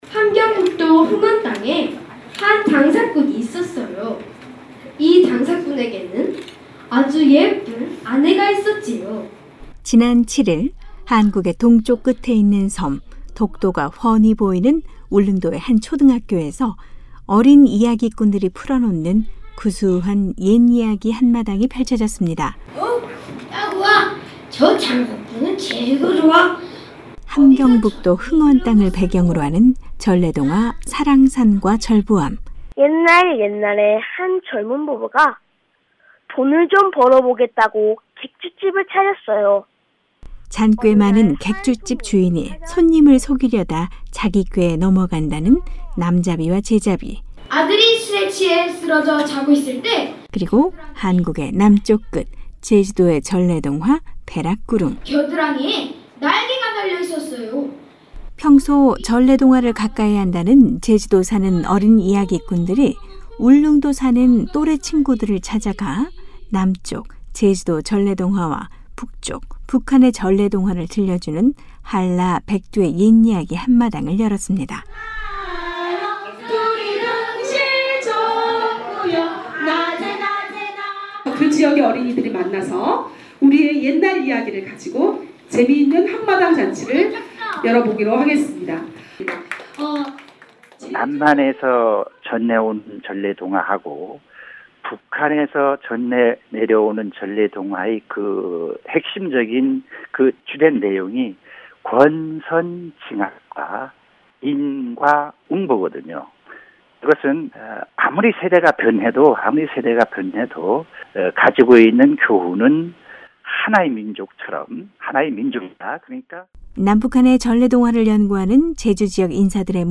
제주도 지역의 옛이야기와 함께 북한의 전래동화를 소개하는 어린 이야기꾼들의 구수한 입답이 눈길을 끌었습니다.